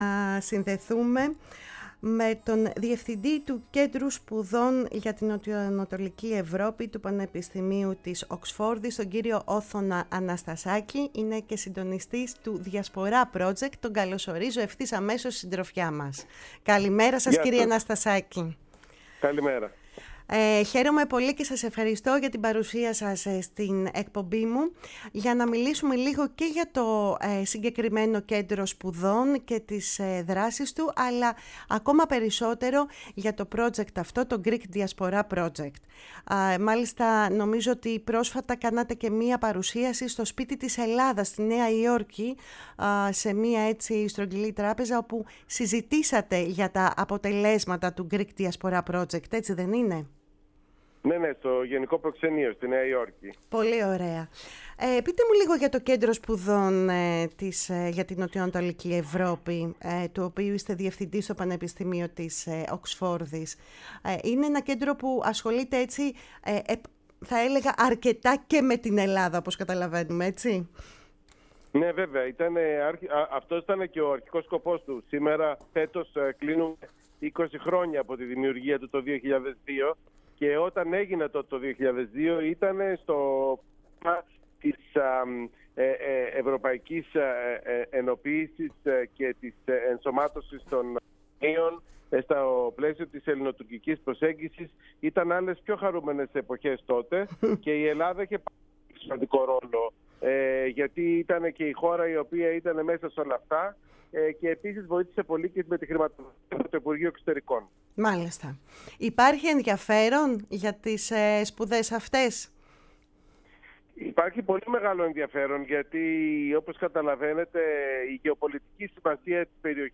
ήταν καλεσμένος στη «Φωνή της Ελλάδας» και συγκεκριμένα στην εκπομπή «Κουβέντες μακρινές»